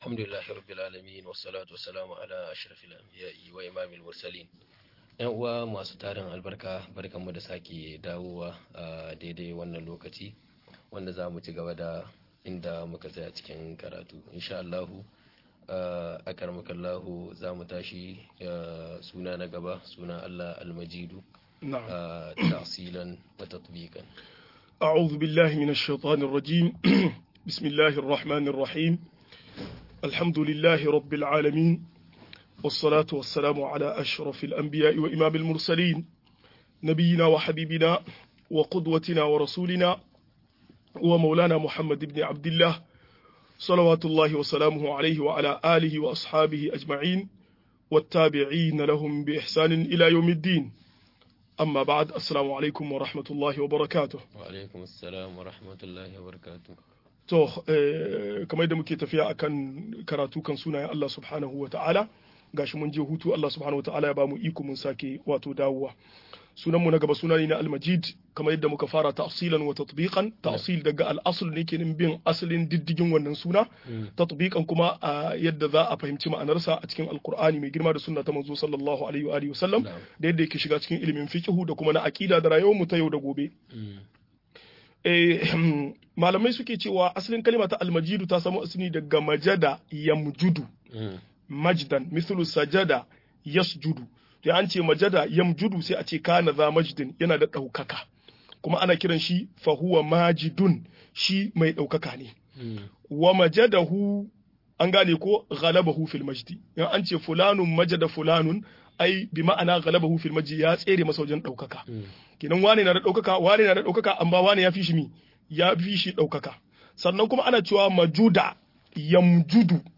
Sunayen Allah da siffofin sa-16 - MUHADARA